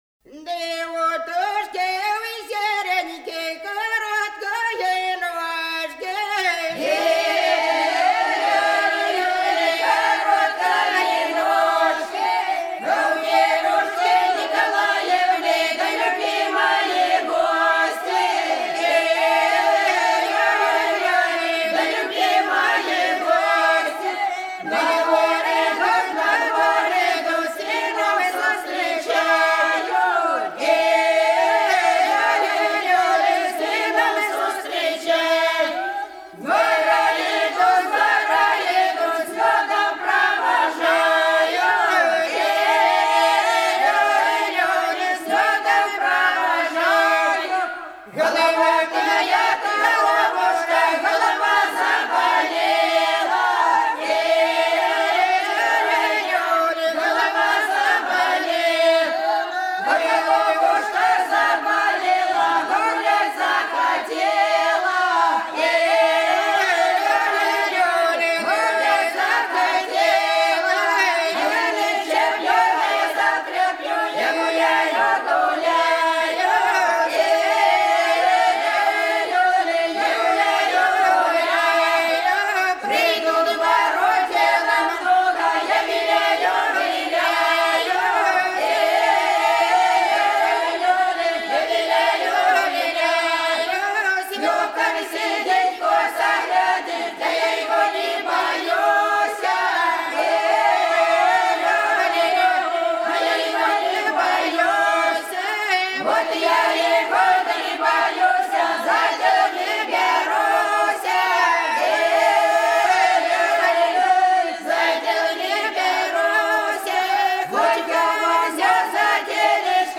Голоса уходящего века (Курское село Илёк) У утушки, у серенькой короткие ножки (карагодная, свадебная величальная)